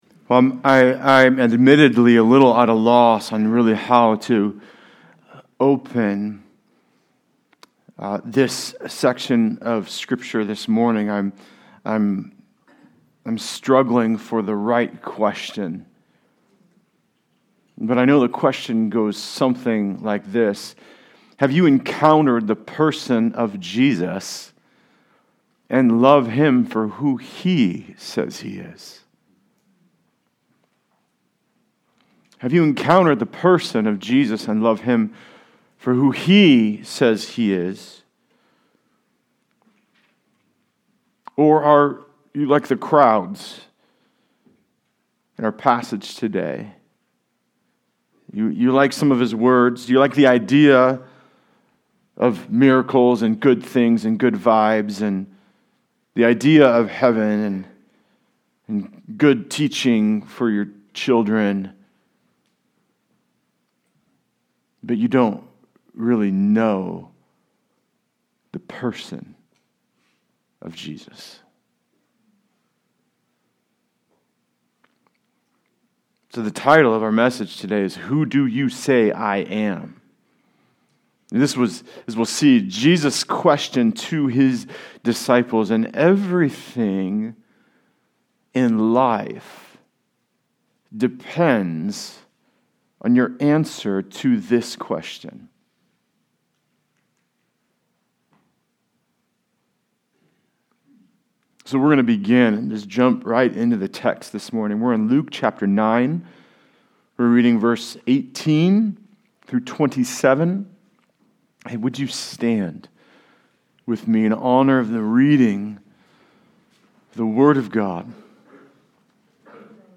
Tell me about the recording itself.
Good News for All People Passage: Luke 9:18-27 Service Type: Sunday Service Related « Psalm 119